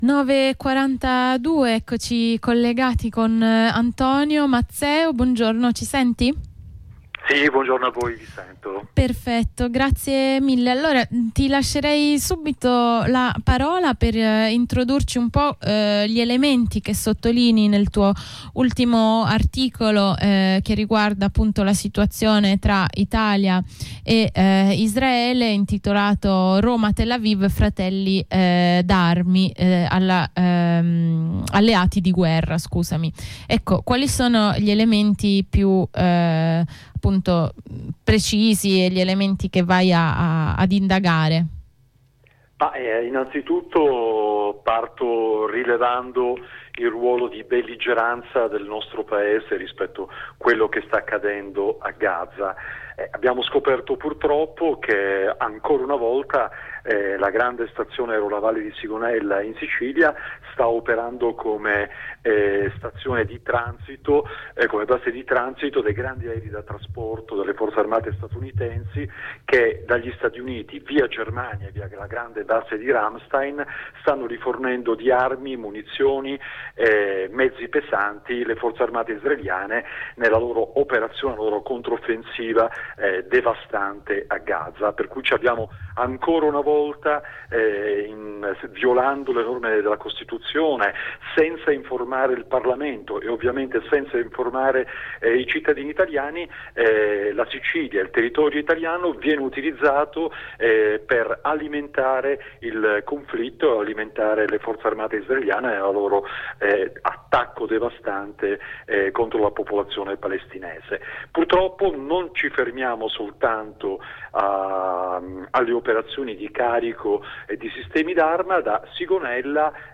abbiamo intervistato gli autori di due articoli rispettivamente sulle due questioni